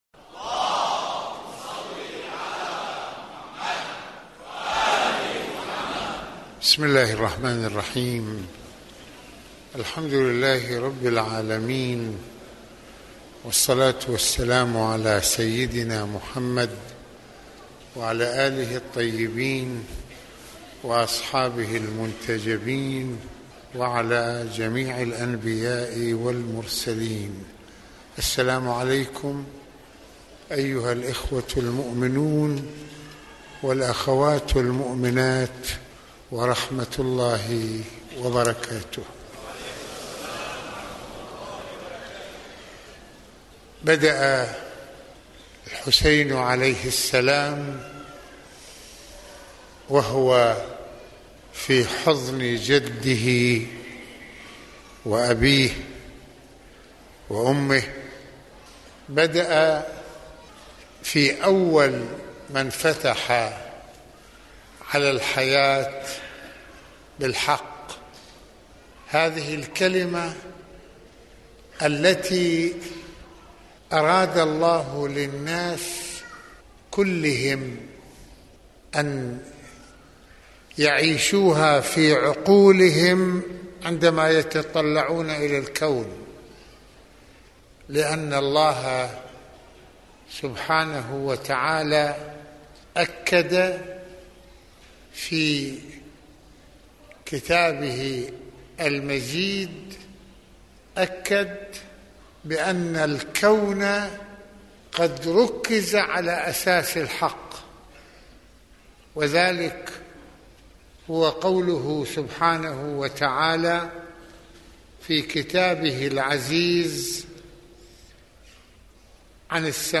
- يتحدث المرجع السيد محمد حسين فضل الله (رض) في هذه المحاضرة العاشورائية عن محورية الحق في الكون ، ومسيرة النبوة والإمامة القائمةعلى حركة الحق في مواجهة الباطل رغم كل التحديات ، وكيف أن الحسين (ع) انطلق من خط الإسلام وما علينا نحن كأمّة أن نتعلمه من كل ذلك ...